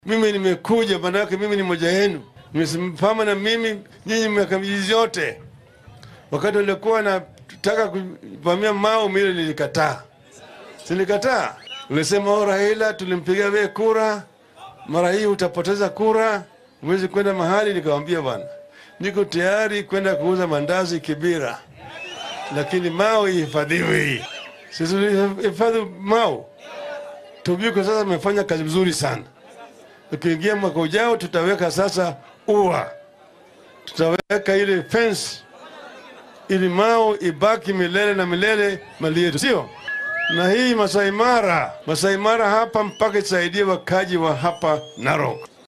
DHAGEYSO:Raila oo isku soo bax siyaasadeed ka sameeyay Narok
Musharraxa madaxweyne ee isbeheysiga Azimio la Umoja-One Kenya ,Raila Odinga ayaa shalay isku soo bax siyaasadeed ka sameeyay ismaamulka Narok. Waxaa uu shacabka halkaasi ku nool u ballanqaaday in haddii uu hoggaanka wadanka qabto maamulkiisa uu ilaalin doono keynta Mau ee dowlad deegaankaasi. Sidoo kale waxaa uu sheegay inuu xaqijin doono in dadweynaha Narok ay si buuxda uga faa’iidaystaan dhaqashada xoolaha oo ay inta badan nolol ahaan ku tiirsan yihiin.